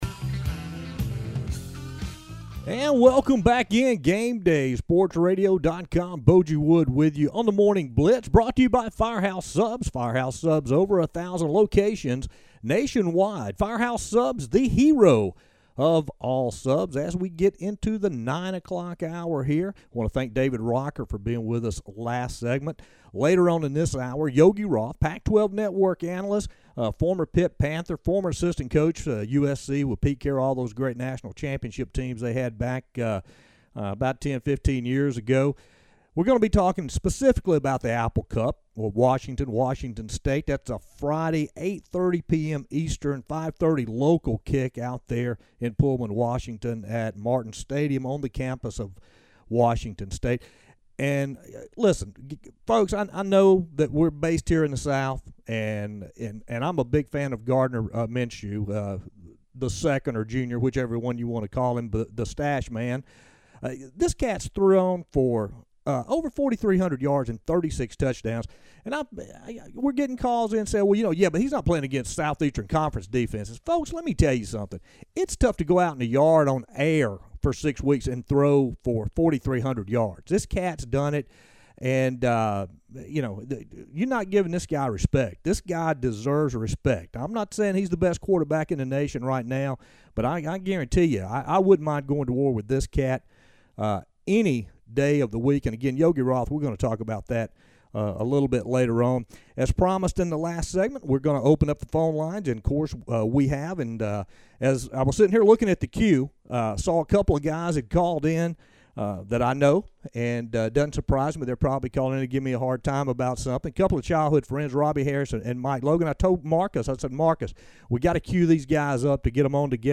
Featured Call